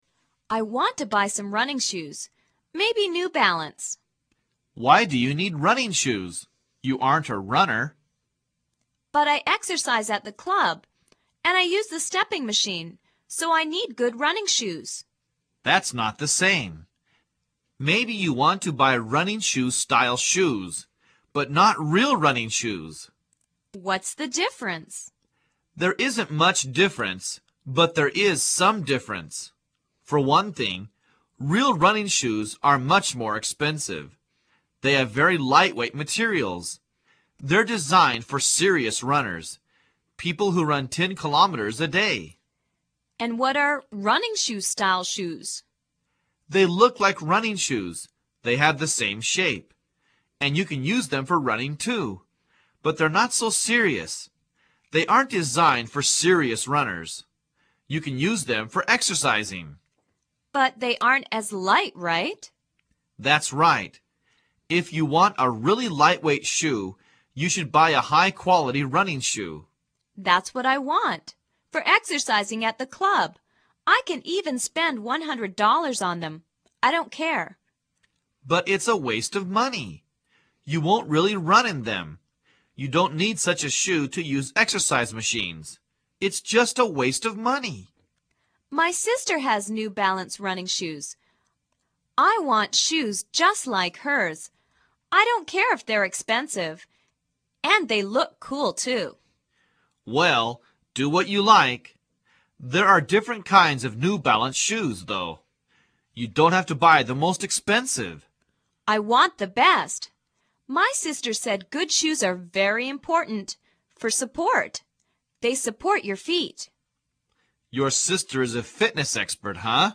购物英语对话 第10讲:买鞋